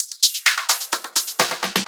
Fill 128 BPM (29).wav